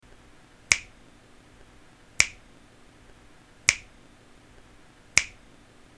Finger Snap Sound Button: Unblocked Meme Soundboard